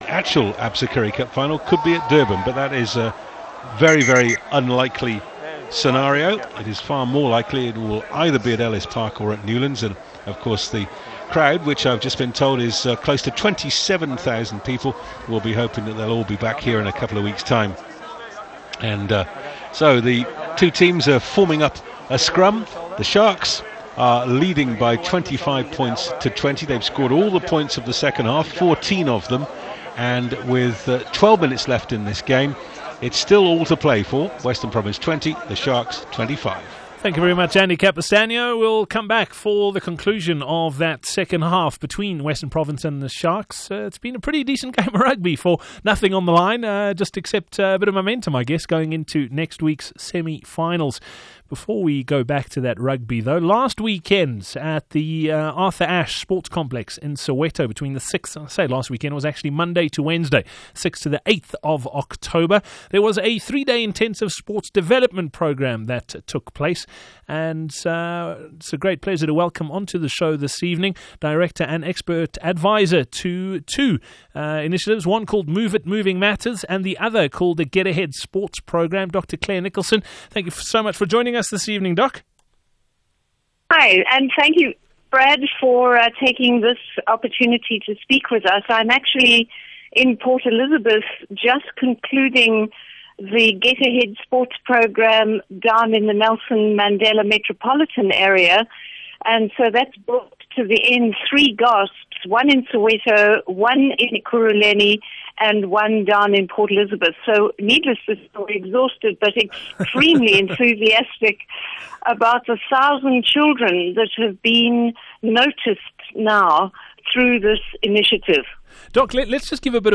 sa_fm_interview.mp2